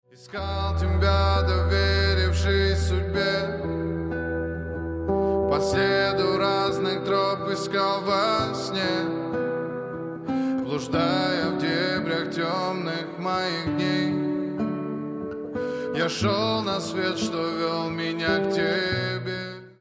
Грустные Рингтоны
Поп Рингтоны